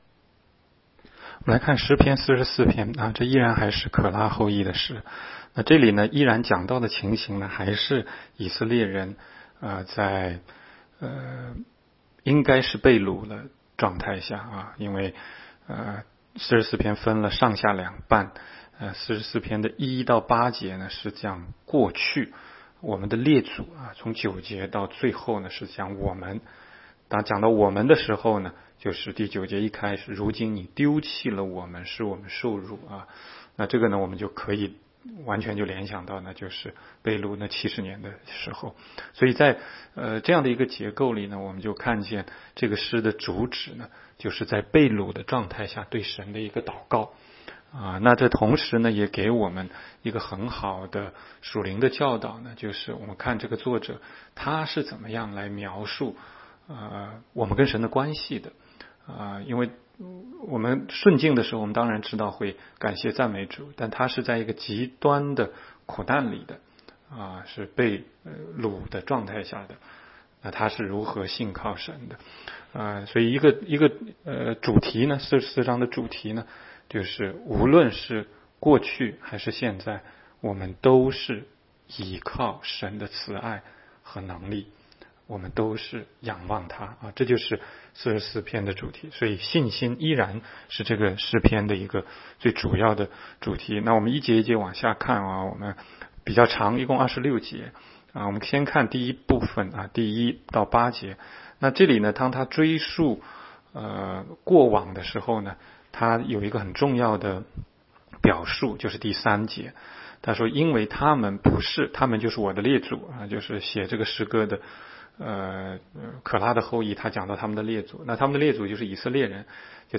16街讲道录音 - 每日读经-《诗篇》44章